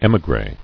[é·mi·gré]